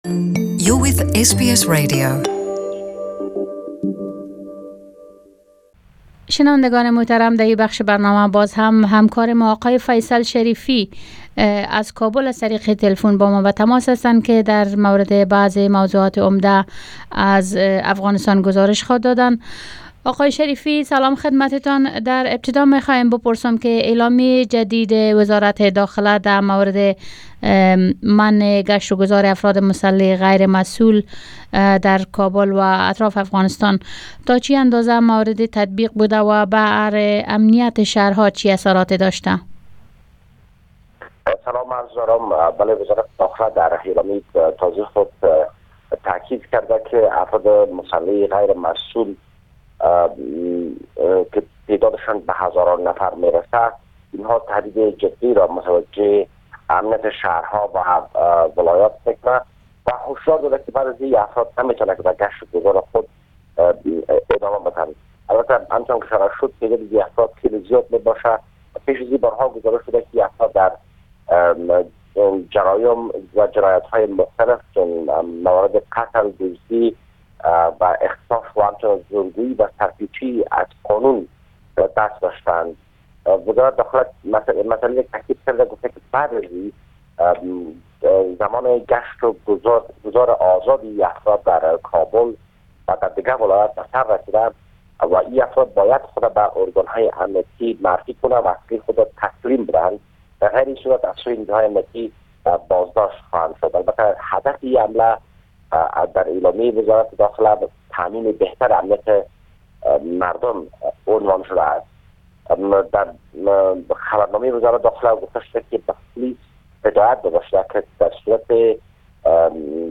Report From Afghanistan 09/02/2019